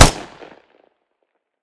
smg-shot-06.ogg